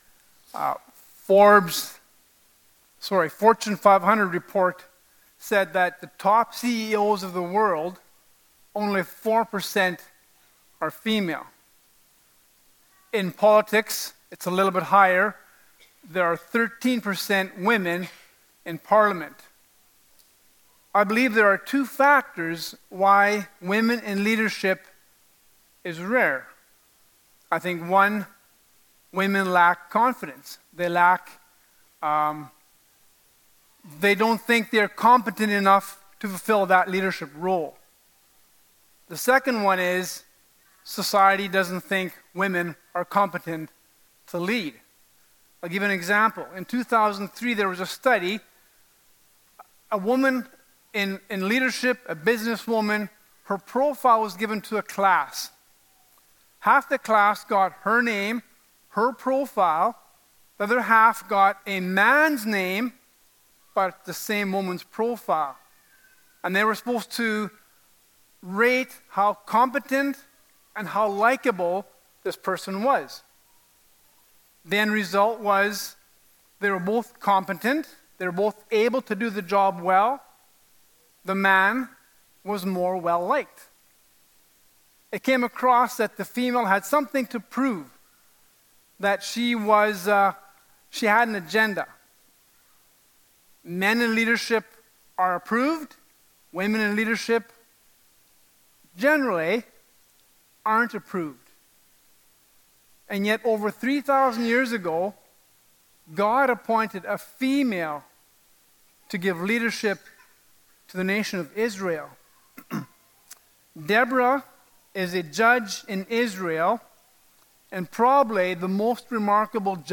Characters of the Bible Passage: Judges 4-5 Service Type: Sunday Morning « Nathanael’s Victory Over Prejudice Hannah